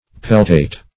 Peltate \Pel"tate\, Peltated \Pel"ta*ted\, a. [Cf. F. pelt['e].